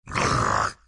软咆哮2
描述：另一个柔软，隆隆的生物咆哮。
标签： 生物 野兽 动物 野兽 令人毛骨悚然 恐怖 怪物 咆哮 吓人 咆哮
声道立体声